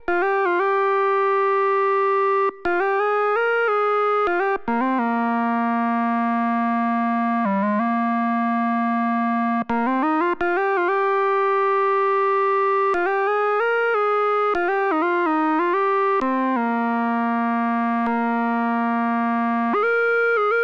09 lead A1.wav